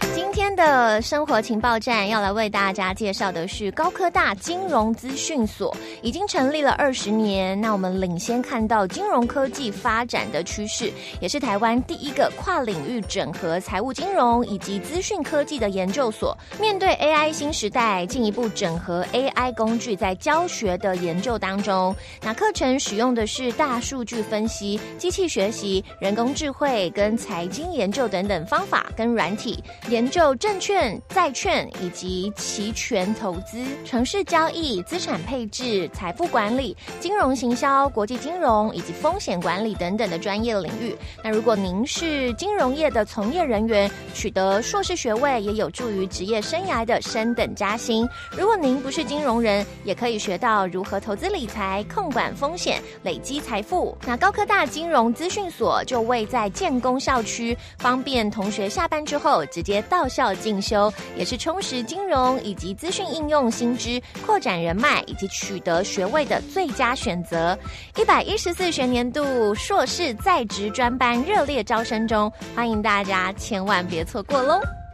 高科大金資所-2025廣播.m4a